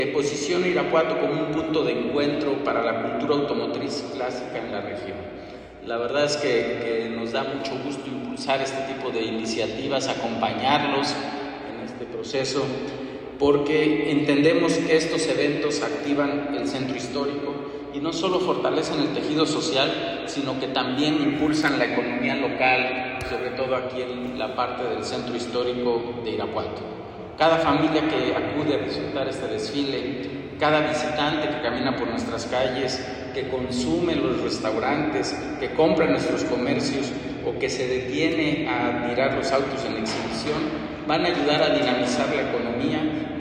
AudioBoletines
Humberto Hernádez Salgado, director de Desarrollo Económico